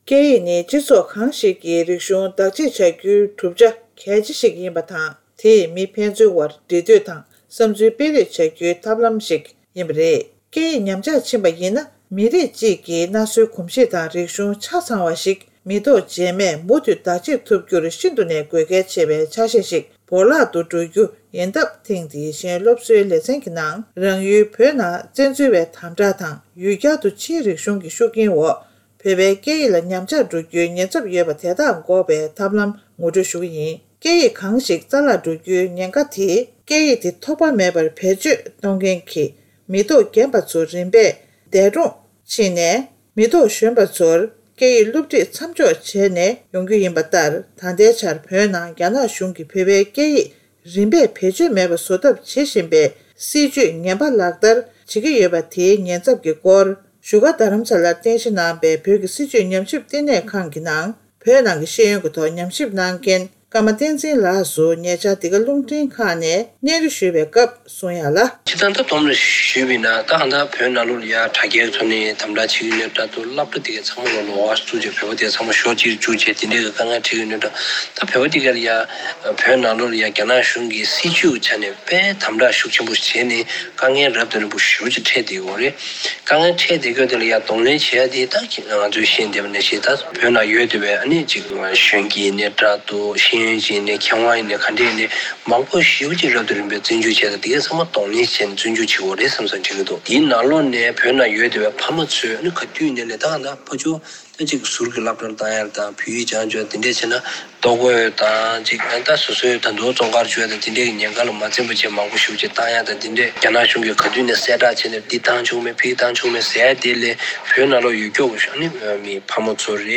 འབྲེལ་ཡོད་མི་སྣར་བཀའ་འདྲི་ཞུས་ཏེ་ཕྱོགས་སྒྲིག་དང་སྙན་སྒྲོན་ཞུས་པར་གསན་རོགས་ཞུ།།